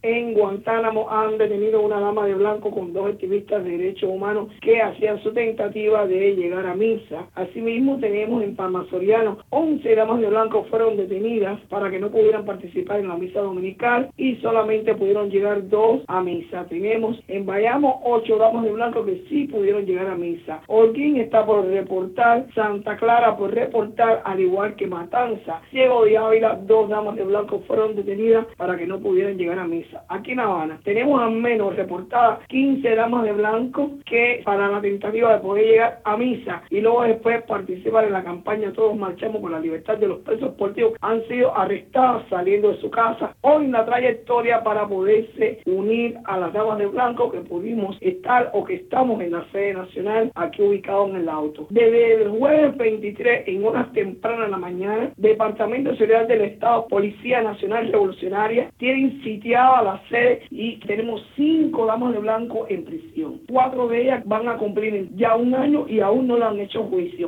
Declaraciones de Berta Soler a Radio Martí sobre arrestos el domingo.